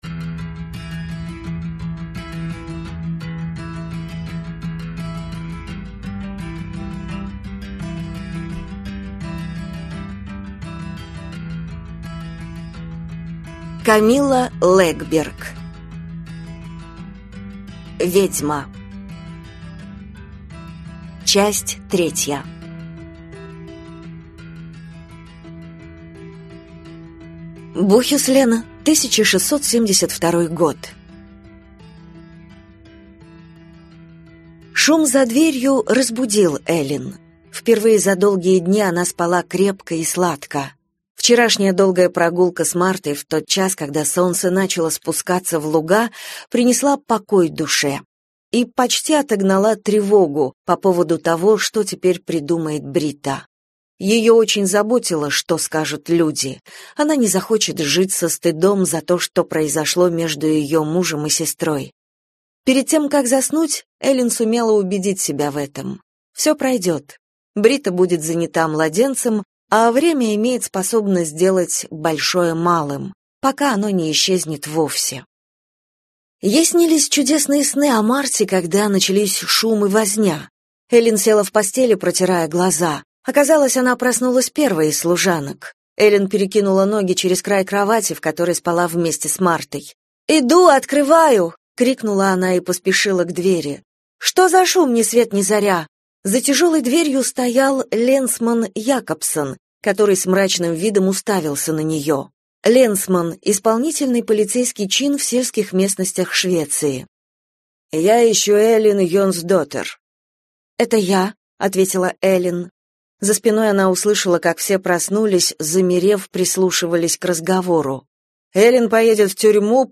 Аудиокнига Ведьма. Часть 3 | Библиотека аудиокниг